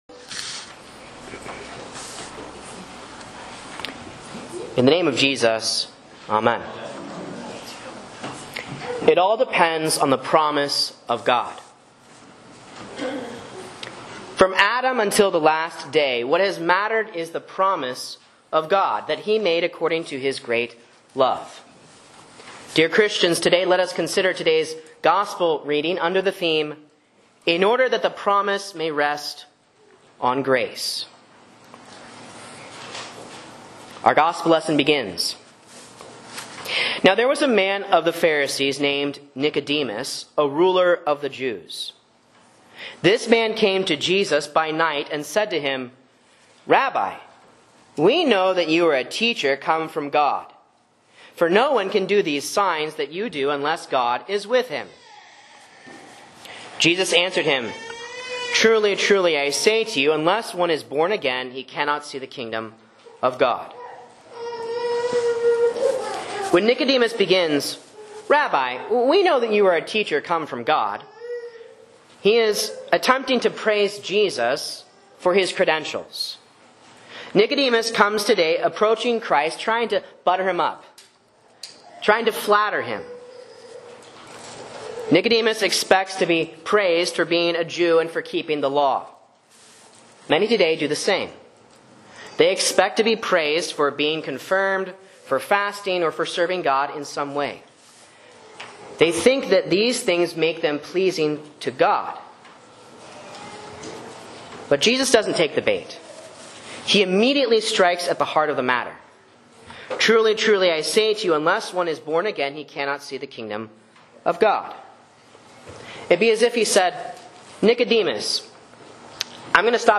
A Sermon on John 3:1-17 for Lent 2 (A)